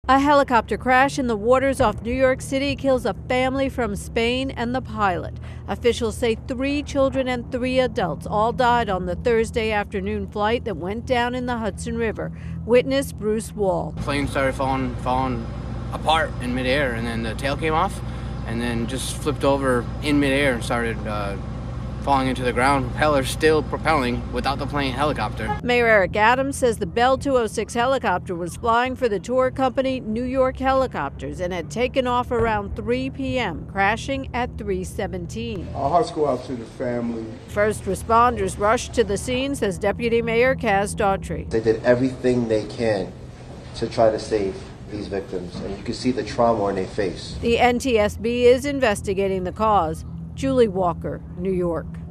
reports on a helicopter crash in the waters off new York City that killed six.